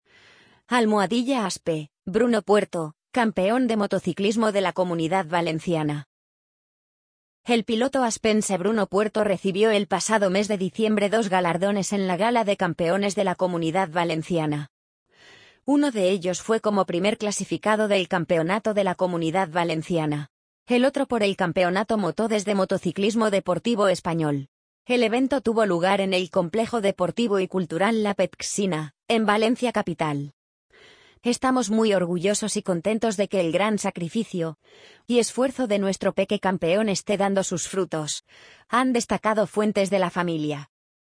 amazon_polly_39464.mp3